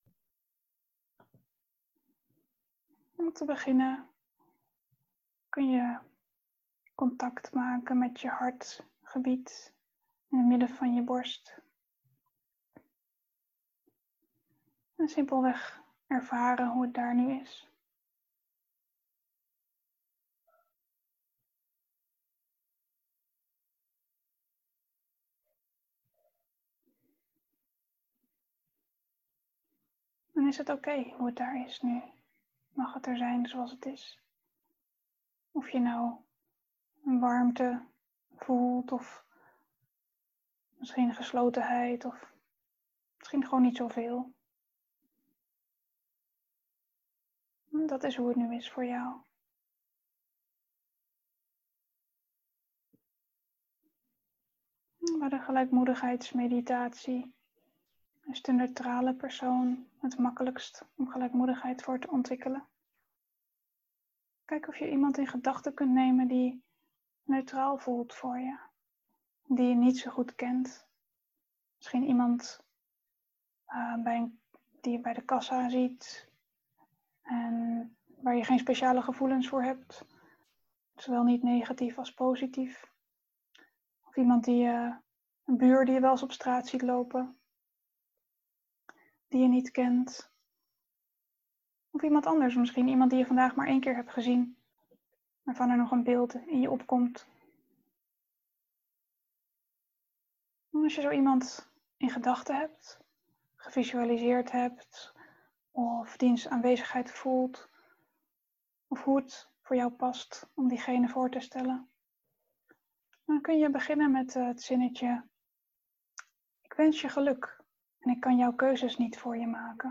Begeleide upekkha-meditatie